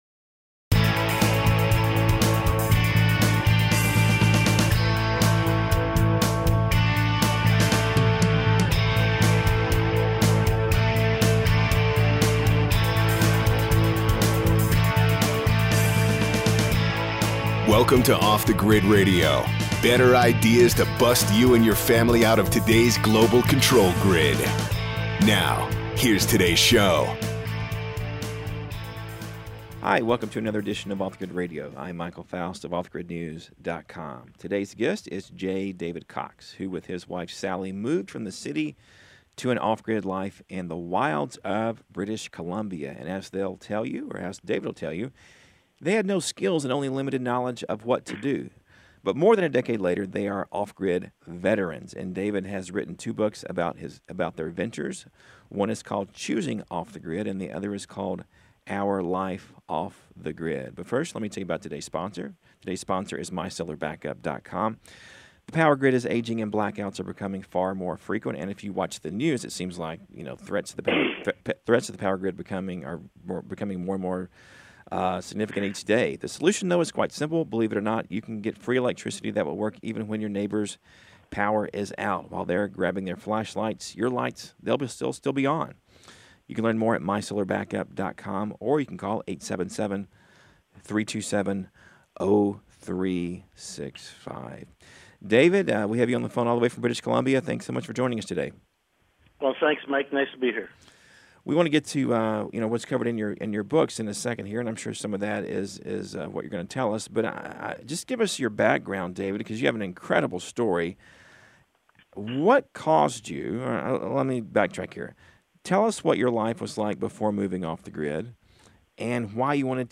off-the-grid-radio-interview.mp3